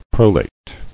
[pro·late]